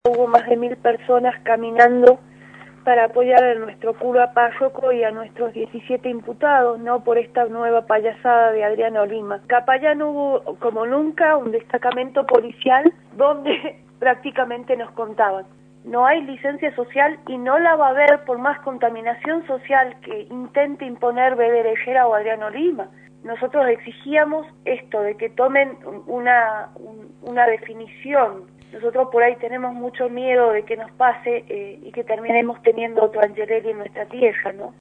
vocera ambientalista, por Radio La Red